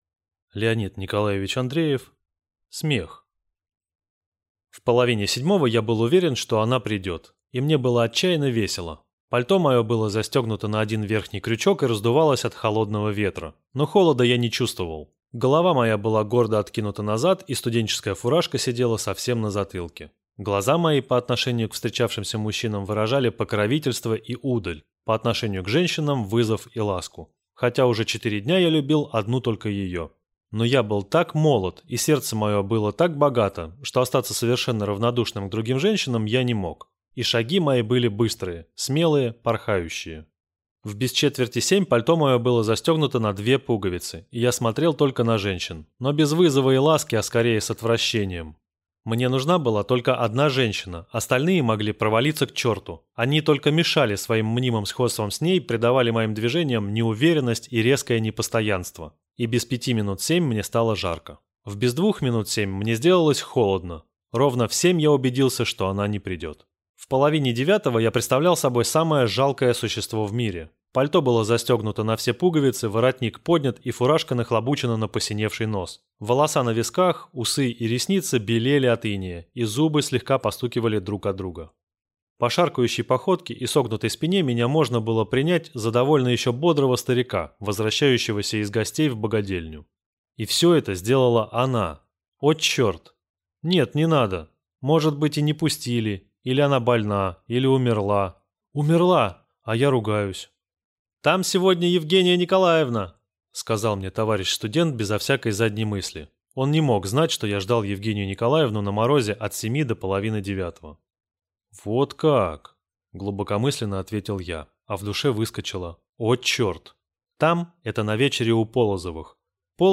Аудиокнига Смех | Библиотека аудиокниг